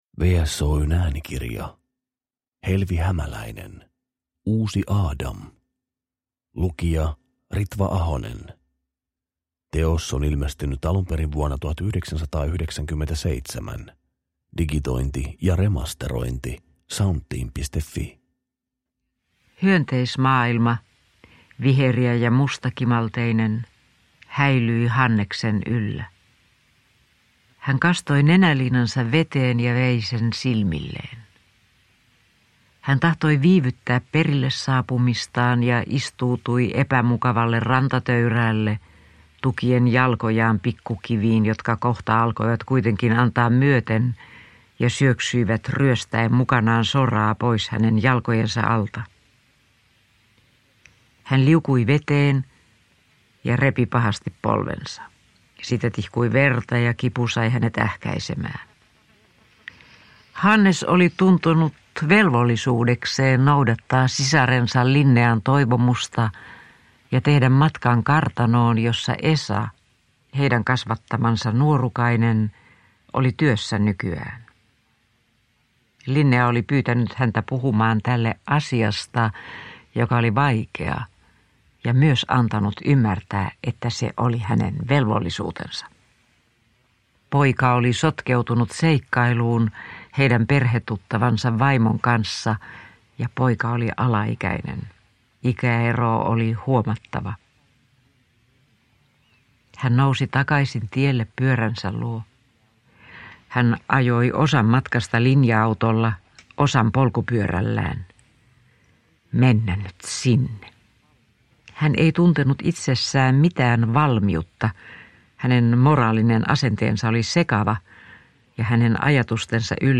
Uusi Aadam – Ljudbok – Laddas ner